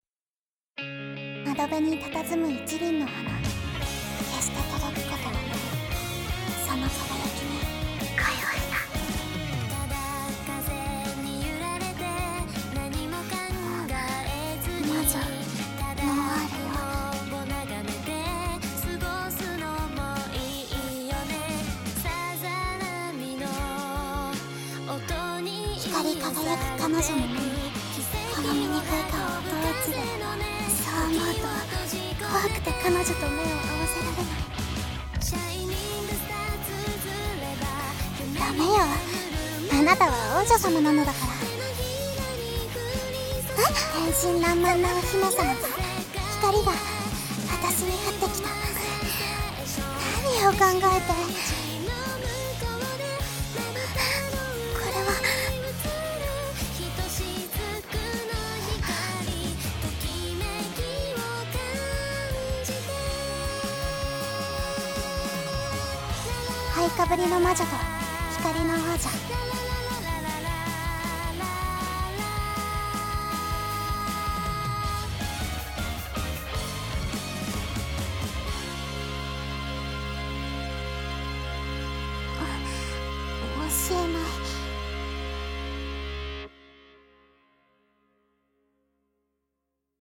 【二人声劇】灰被りの魔女と光の王女